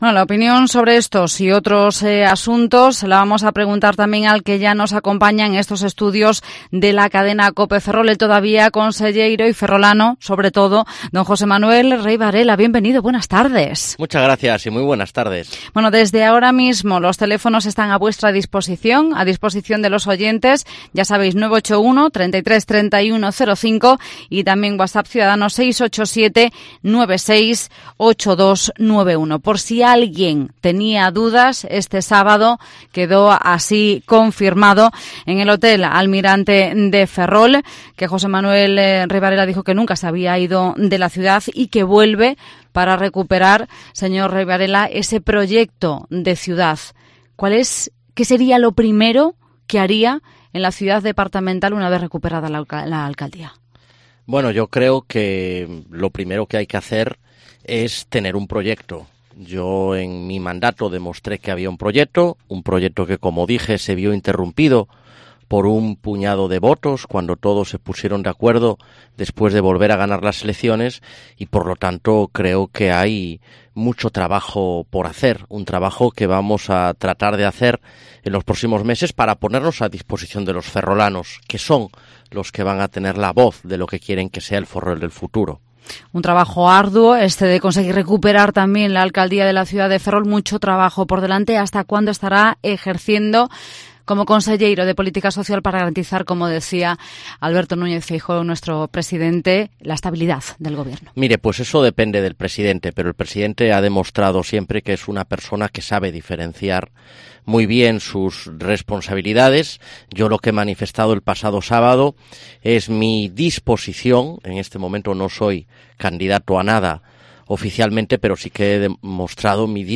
José Manuel Rey Varela estuvo hoy en los estudios de Cope Ferrol